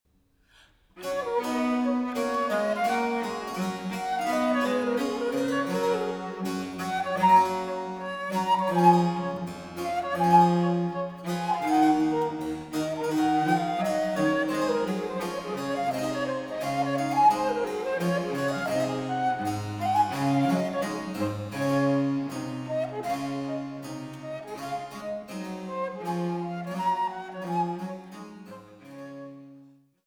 Gracieusement